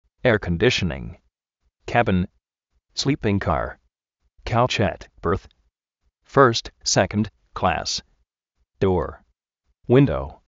éar kondíshnin